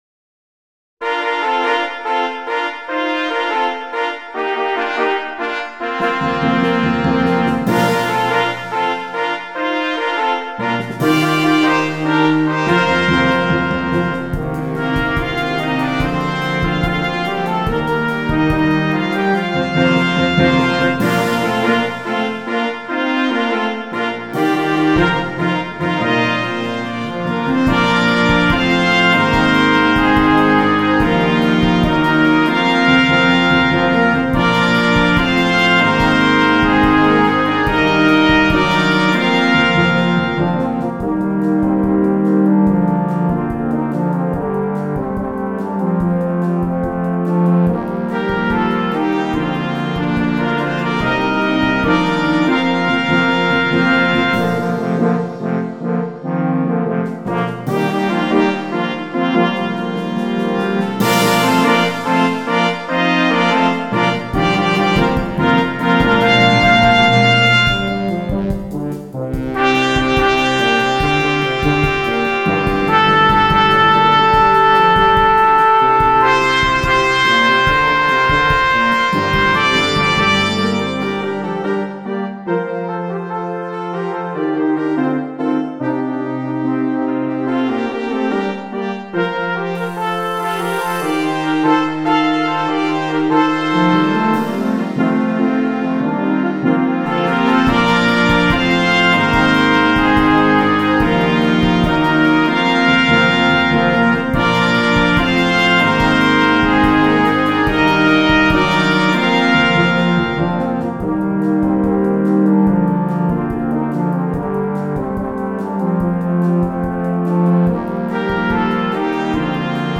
2. Brass Band
Full Band
without solo instrument
Entertainment